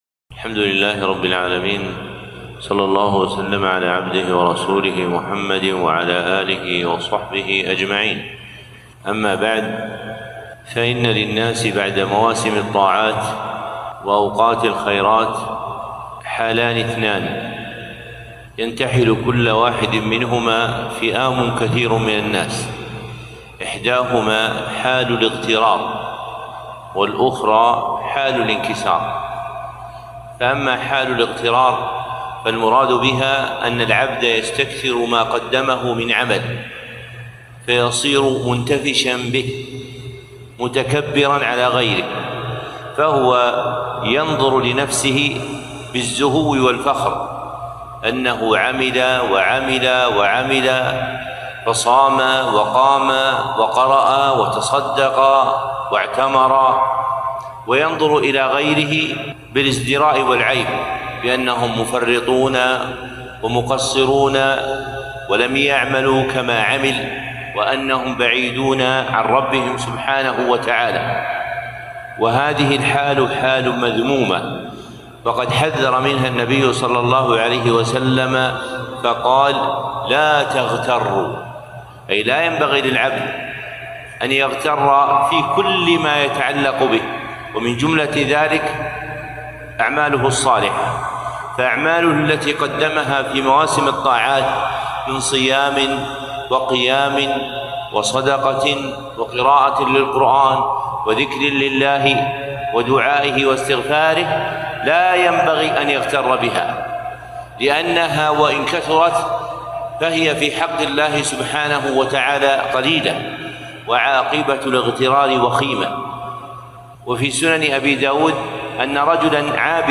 كلمة - ختم رمضان.. بين الاغترار والانكسار